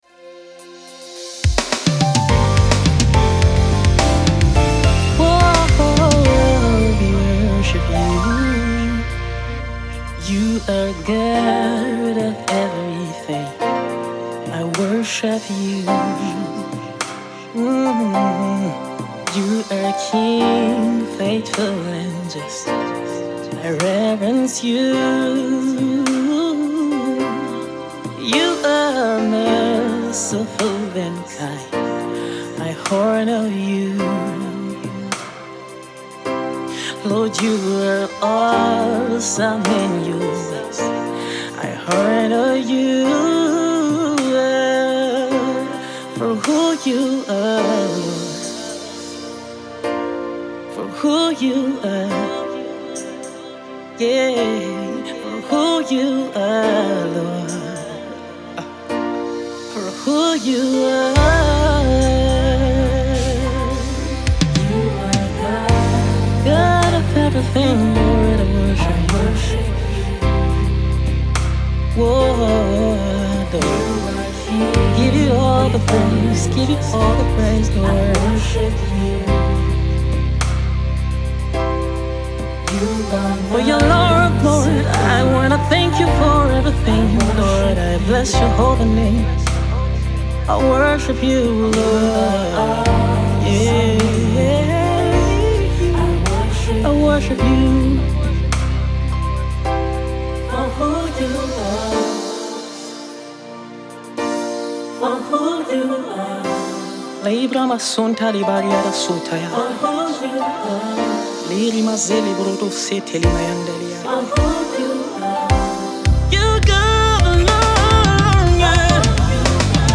is a heartfelt worship song
With an easy to sing along lyrics